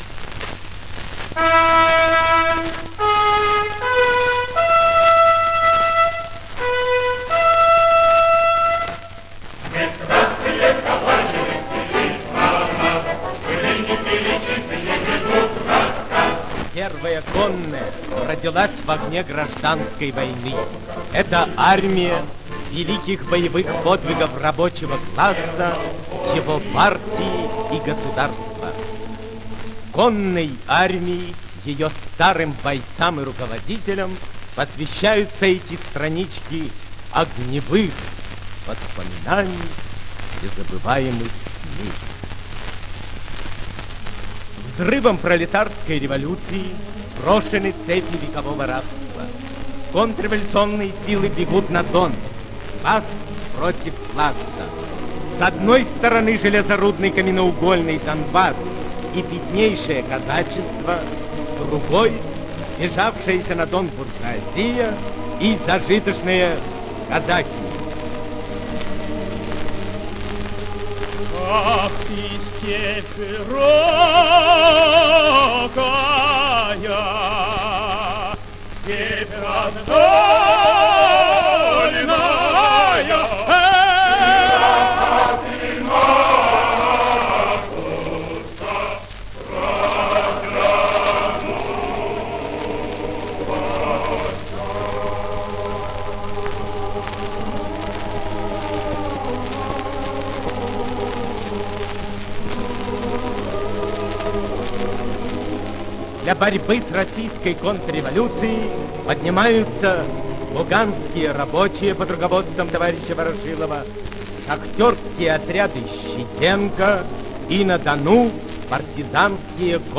чтец